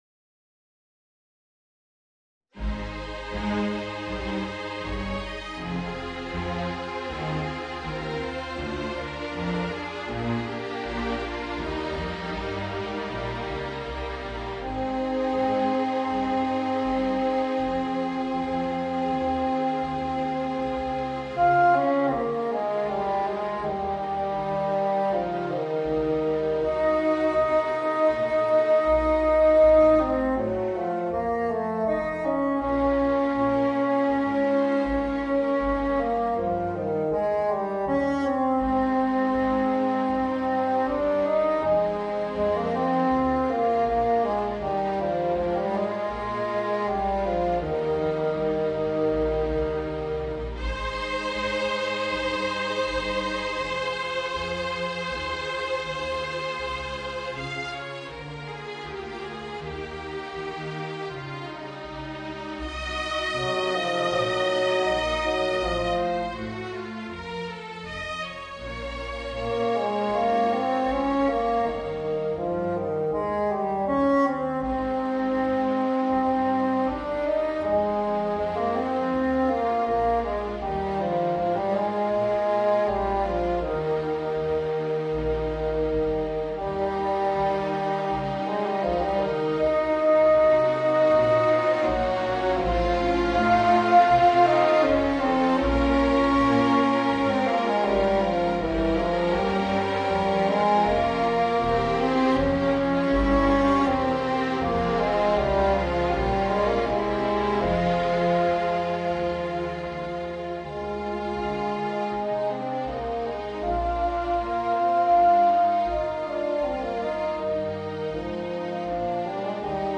Voicing: Bassoon and String Orchestra